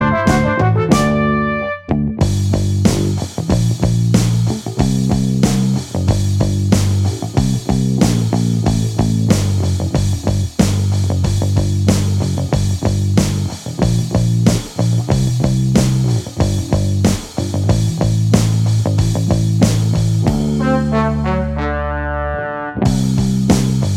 Minus Guitars Pop (1960s) 2:05 Buy £1.50